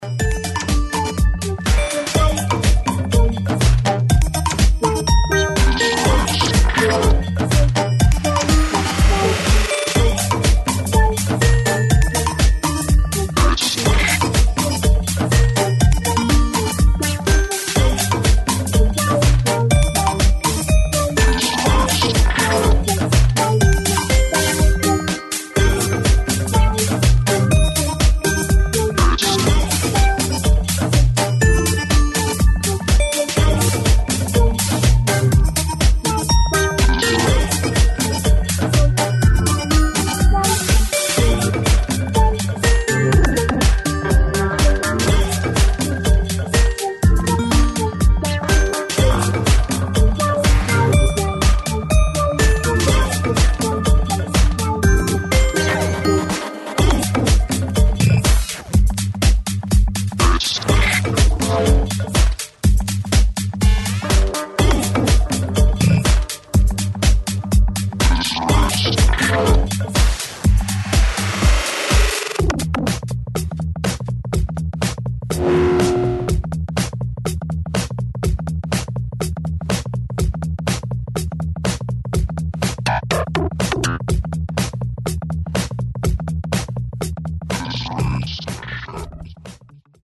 TECH HOUSE